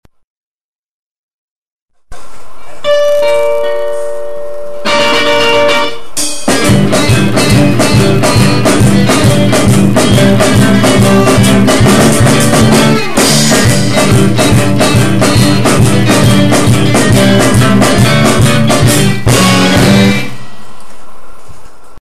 guitar/vocals
bass/backvocals
drums/backvocals